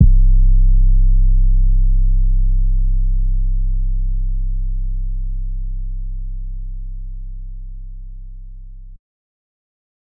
up 808.wav